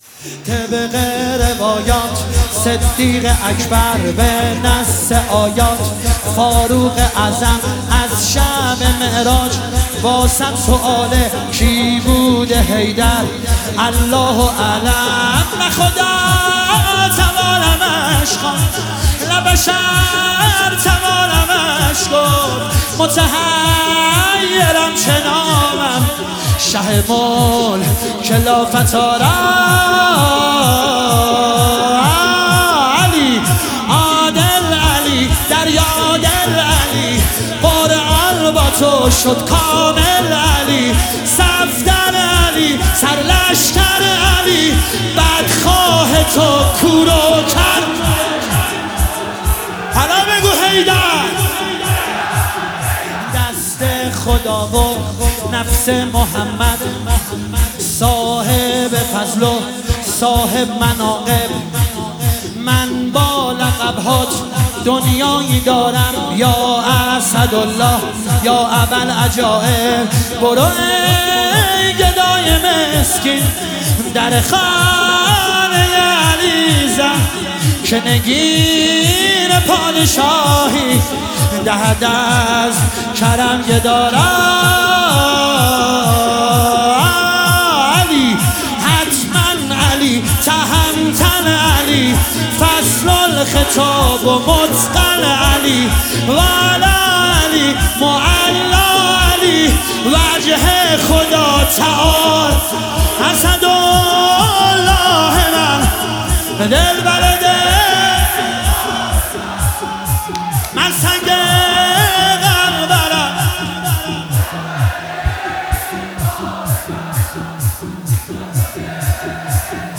دانلود مداحی شور
حرم شهدای گمنام شهرستان ملارد
فاطمیه دوم 1403